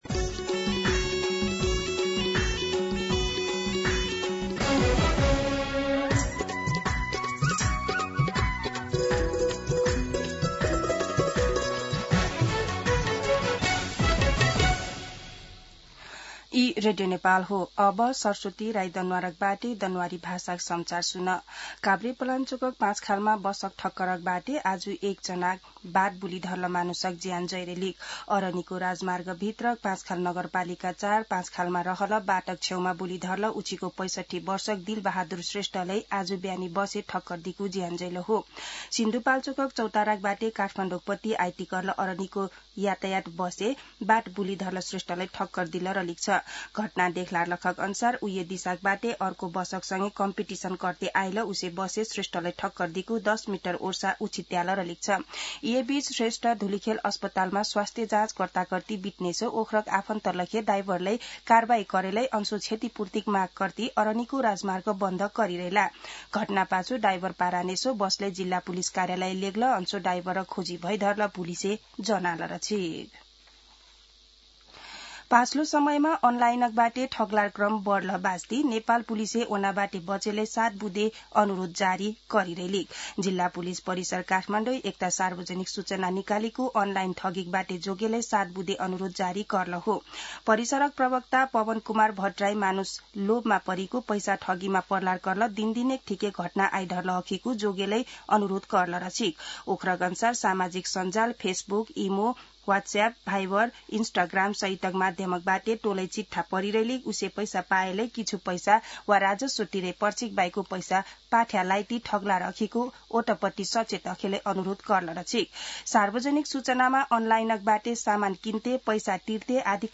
दनुवार भाषामा समाचार : ४ मंसिर , २०८२
Danuwar-News-8-4.mp3